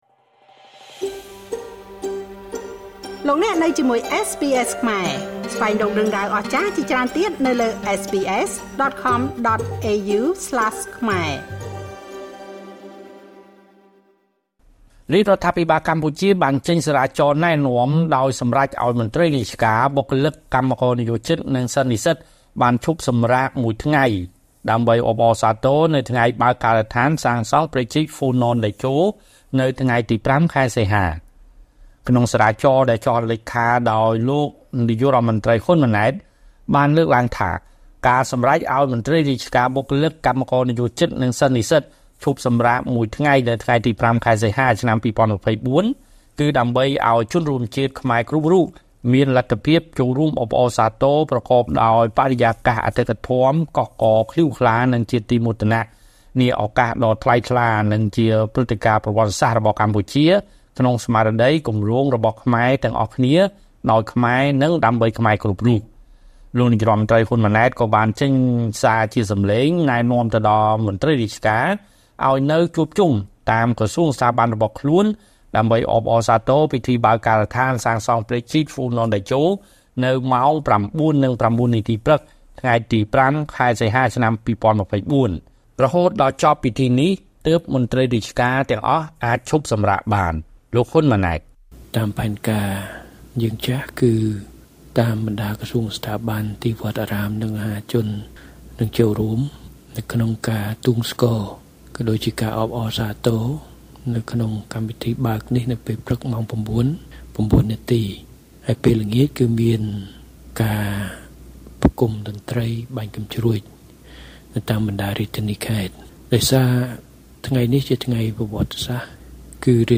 លោកនាយករដ្ឋមន្ត្រី ហ៊ុន ម៉ាណែត ក៏បានចេញសារជាសំឡេង ណែនាំដល់មន្ត្រីរាជការ ឱ្យនៅជួបជុំតាមក្រសួងស្ថាប័នរបស់ខ្លួន ដើម្បីអបអរសាទរពិធីបើកការដ្ឋានសាងសង់ព្រែកជីក ហ្វូណន តេជោ នៅម៉ោង ៩ និង៩នាទីព្រឹក ថ្ងៃទី៥ ខែសីហា ឆ្នាំ២០២៤។